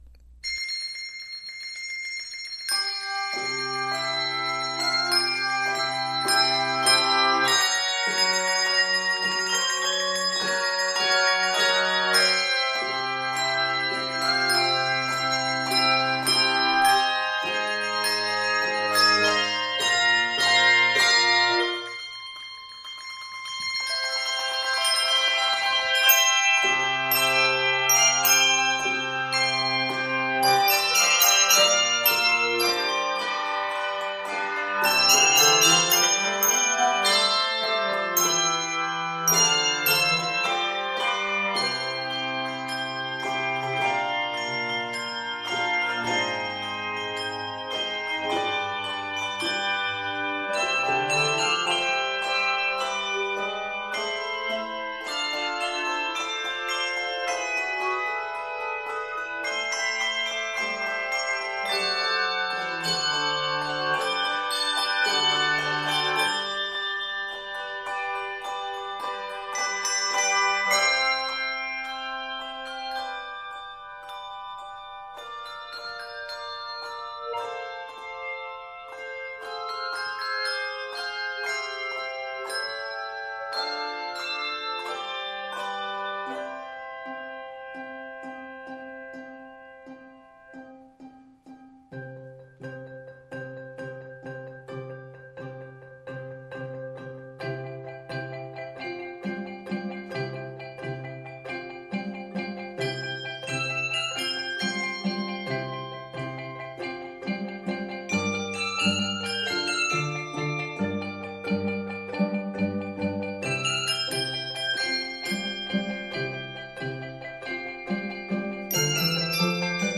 Key of C Major. 97 measures.